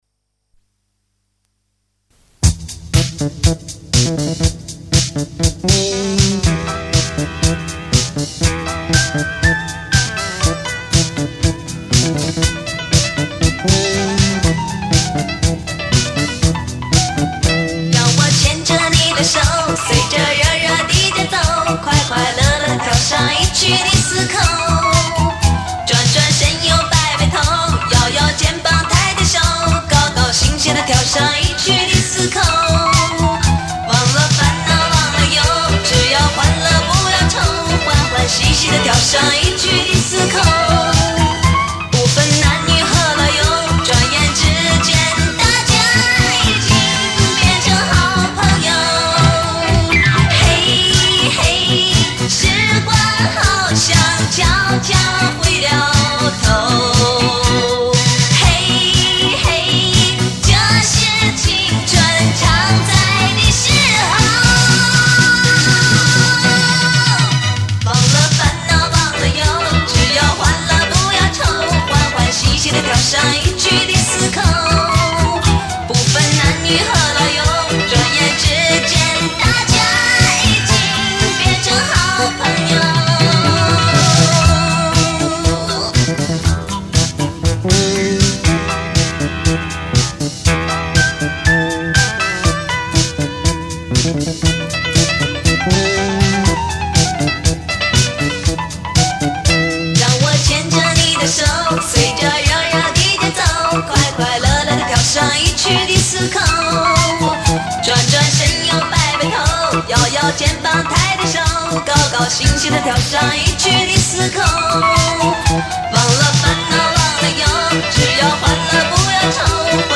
音质一般，介意勿下！